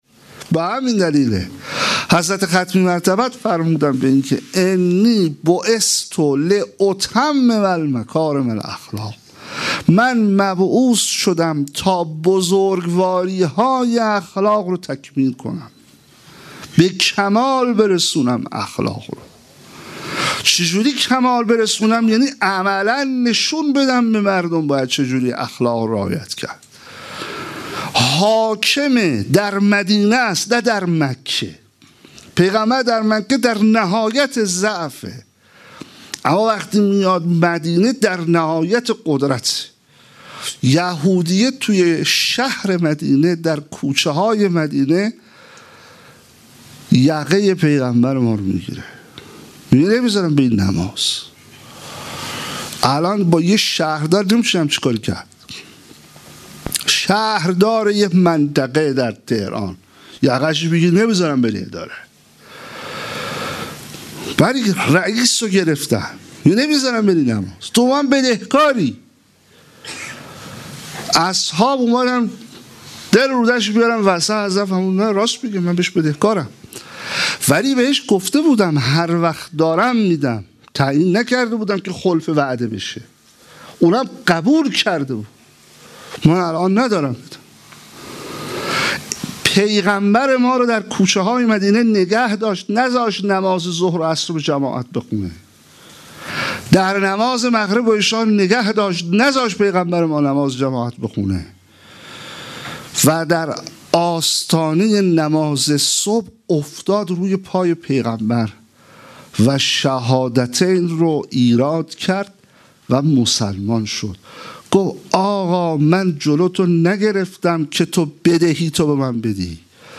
13 اسفند 96 - هیئت احرار الحسین - سخنرانی